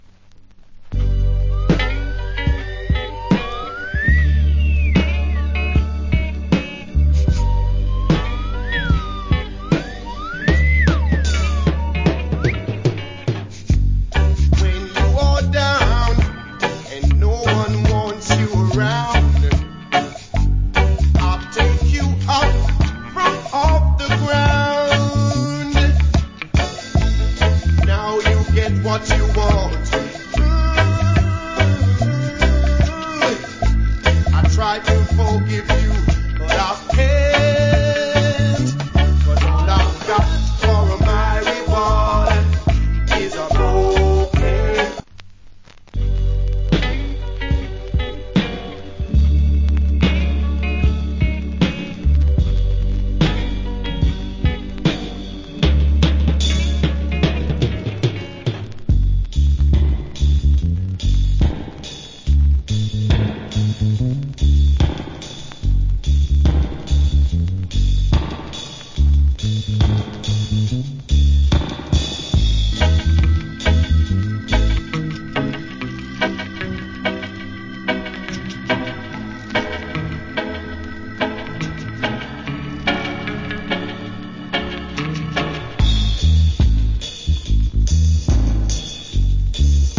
Cool Reggae.